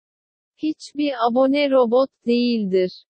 robot-dlive.mp3